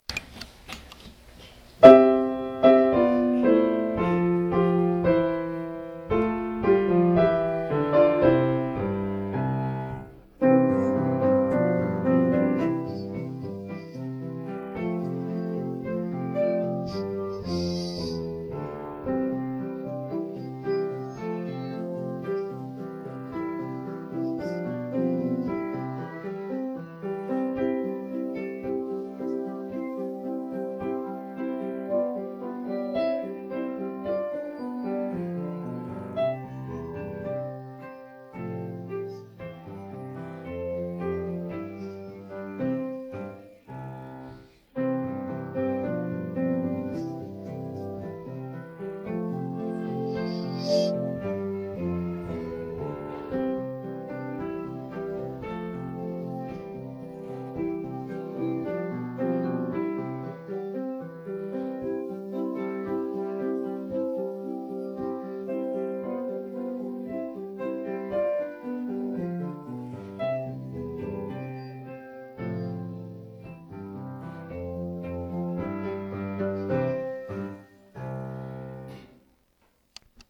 1_toyomizusyogakko_Instrumental.mp3